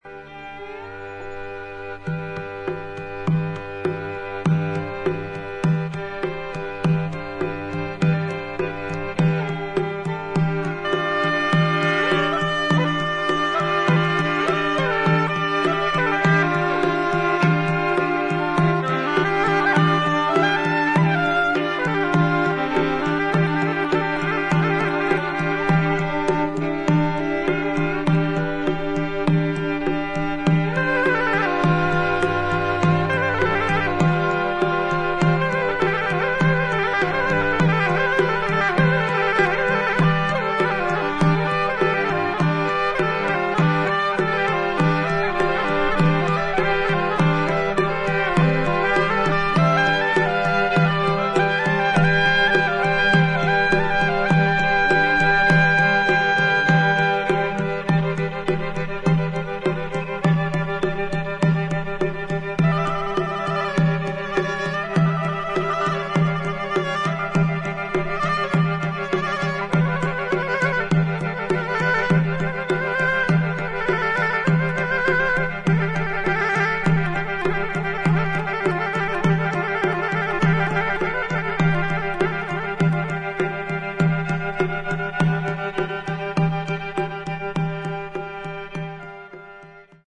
インドのラーガとヨーロッパ伝統音楽にロックの前衛性を織り交ぜ